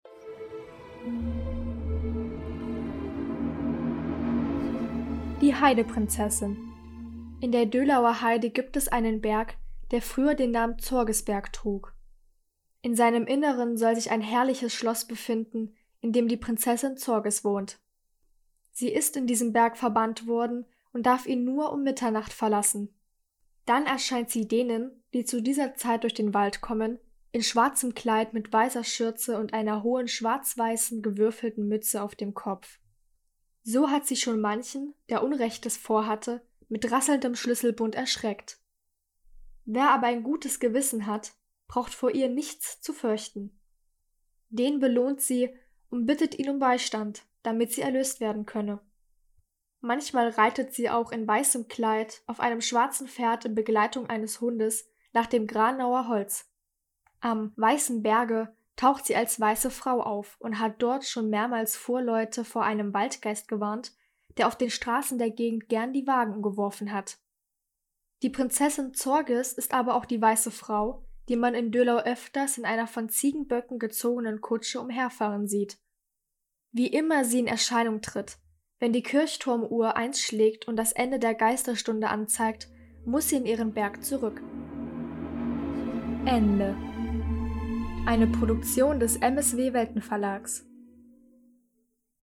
Sagen aus der Umgebung von Halle (Saale), gelesen von der